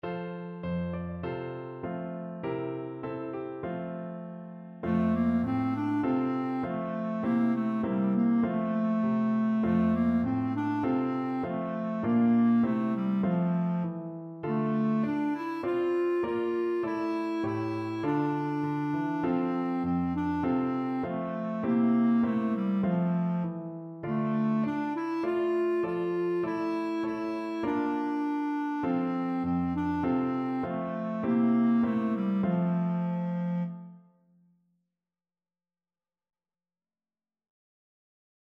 4/4 (View more 4/4 Music)
Traditional (View more Traditional Clarinet Music)